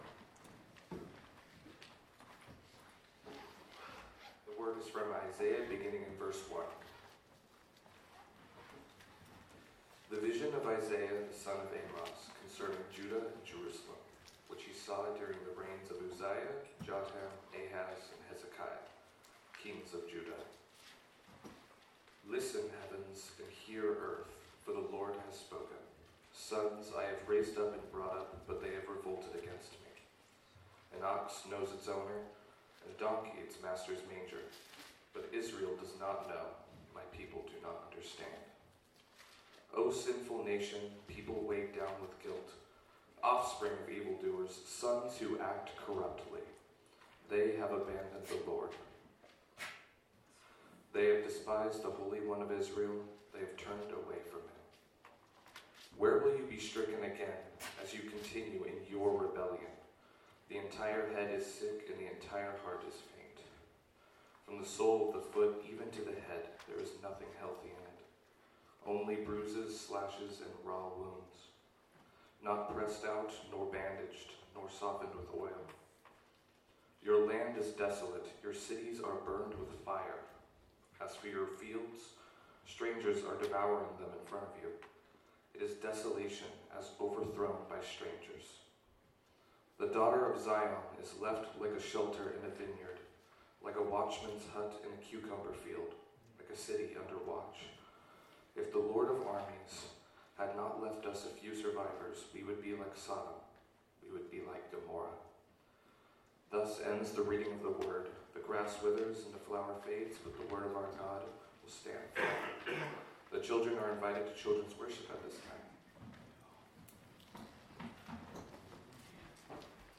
Isaiah1:1-9 Salvation from the Ashes of History(5-11-25) | Sunrise Church McMinnville, OR